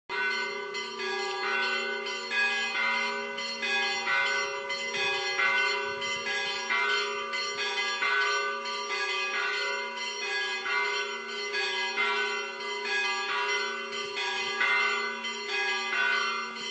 יום לפני שחזרתי התעוררתי לצלילי פעמוני הכנסייה